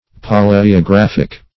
\Pa`l[ae]*o*graph"ic\